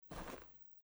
在薄薄的雪地上脚步声－轻－右声道－YS070525.mp3
通用动作/01人物/01移动状态/02雪地/在薄薄的雪地上脚步声－轻－右声道－YS070525.mp3